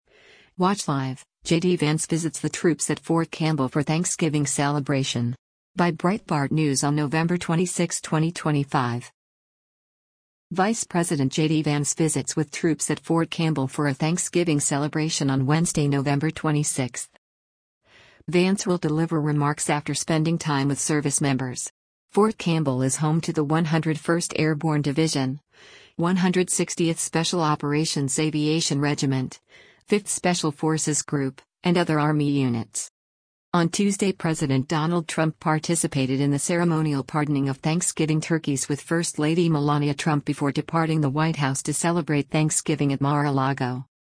Vice President JD Vance visits with troops at Fort Campbell for a Thanksgiving celebration on Wednesday, November 26.
Vance will deliver remarks after spending time with servicemembers.